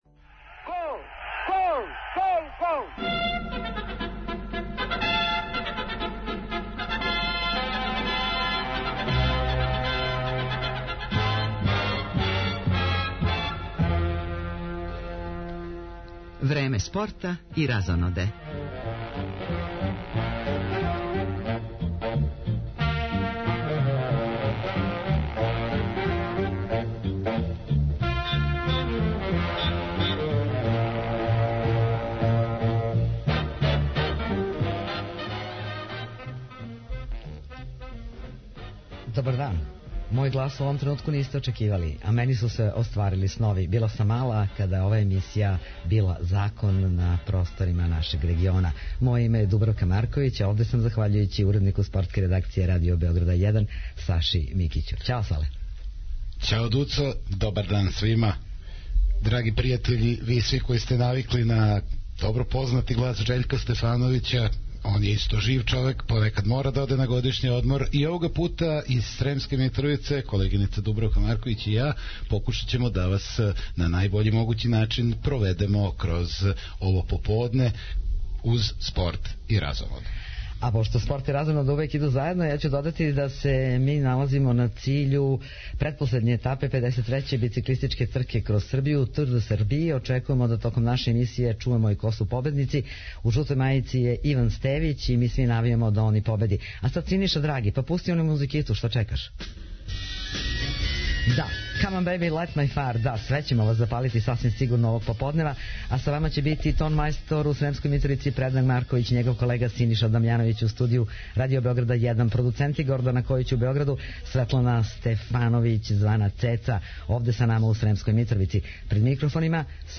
Спорт и музика на путевима римских императора! Ове суботе емисију емитујемо из Сремске Митровице, одакле пратимо финиш међународне бициклистичке трке “Кроз Србију”.